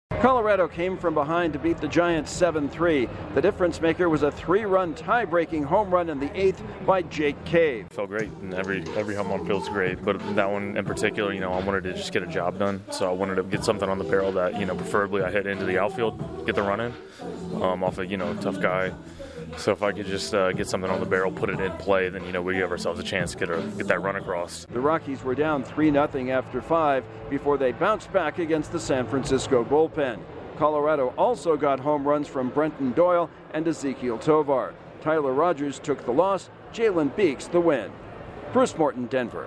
The Rockies rally to beat the Giants. Correspondent